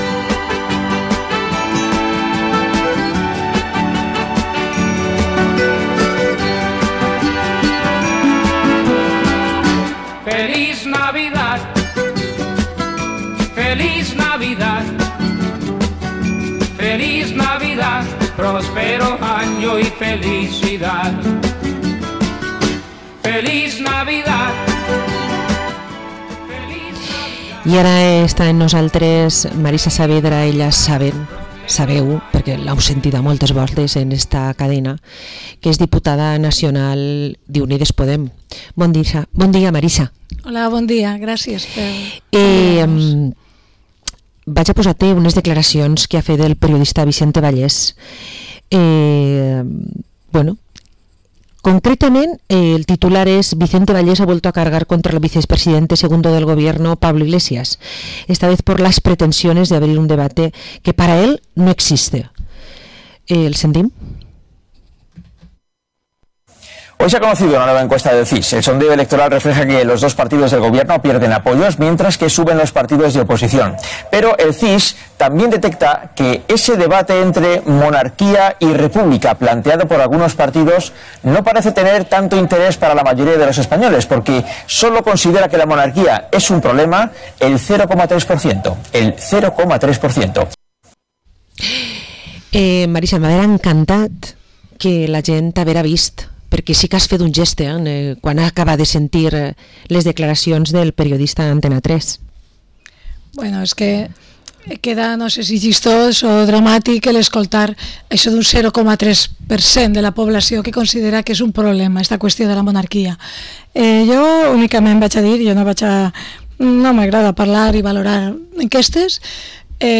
Entrevista a la diputada nacional de Unidas Podemos, Marisa Saavedra